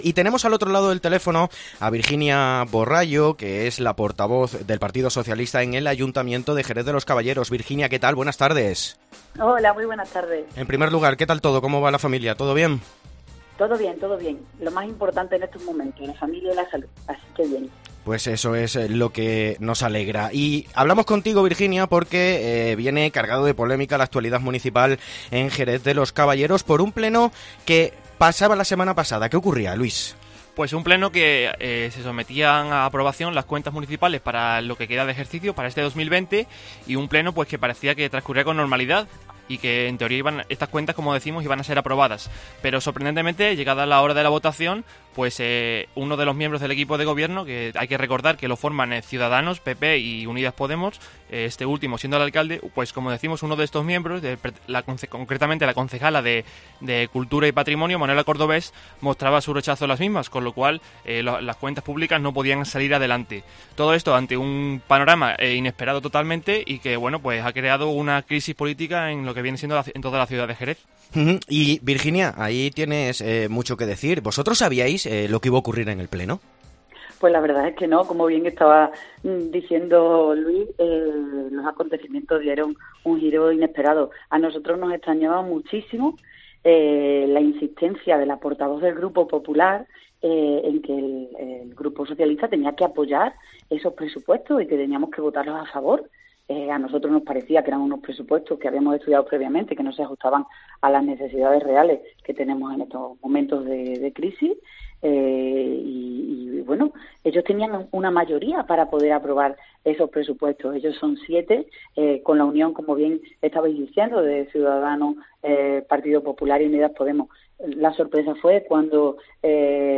Entrevista a Virginia Borrallo, portavoz del PSOE en Jerez de los Caballeros (Badajoz)